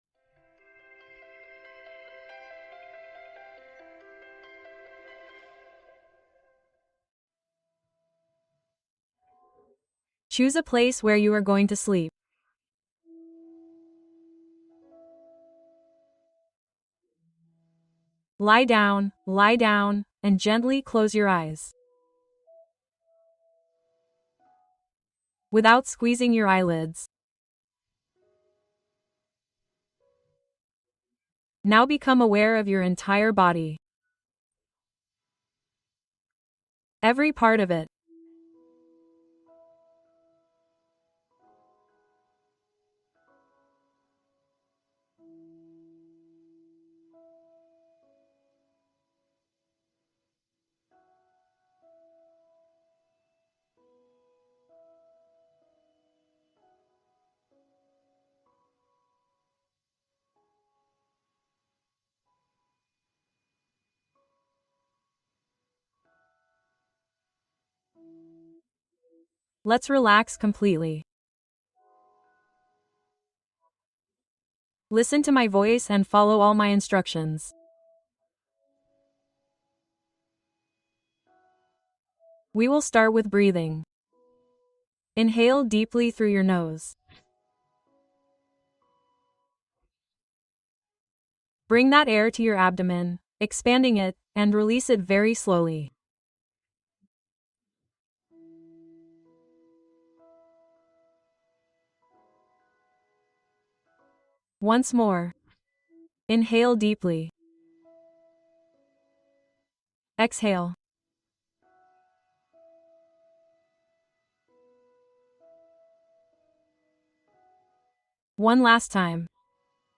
Duerme en paz liberando pensamientos negativos con esta meditación guiada